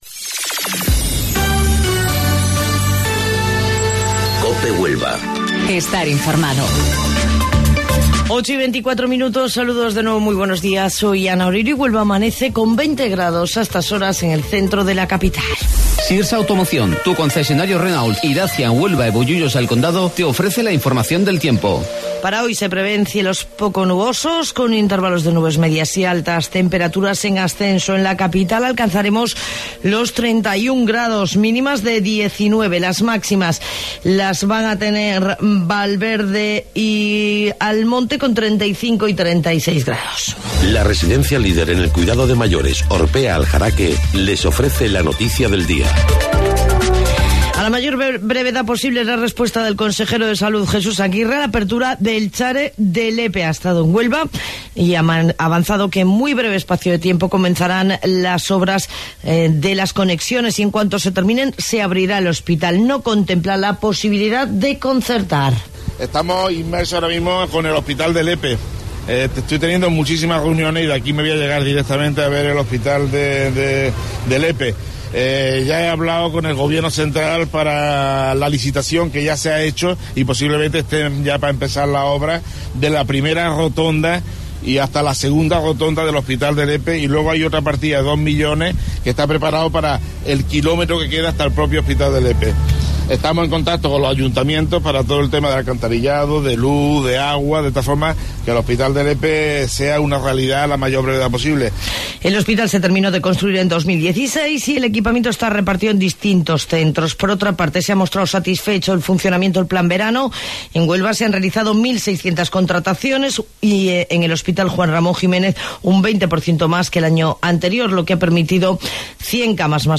AUDIO: Informativo Local 08:25 del 13 de Agosto